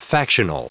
Prononciation du mot factional en anglais (fichier audio)
Prononciation du mot : factional